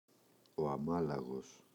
αμάλαγος [aꞋmalaγos]